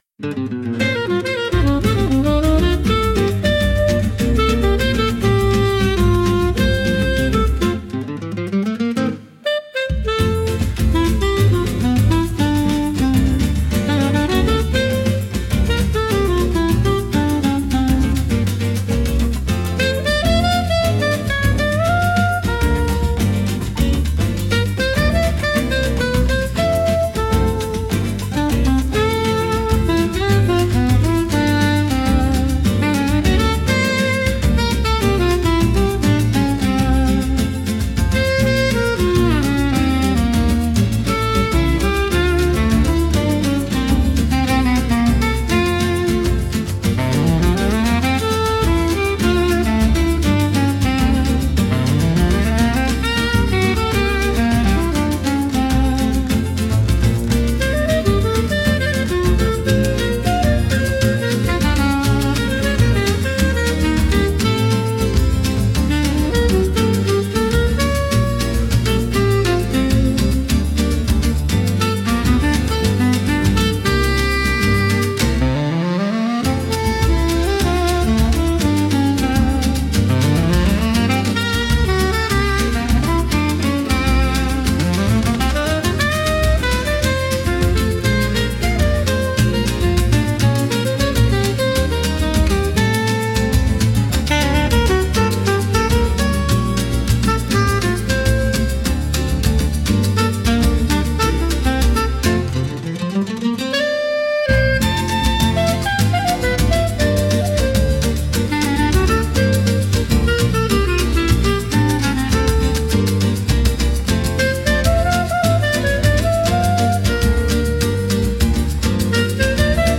música, arranjo e voz: IA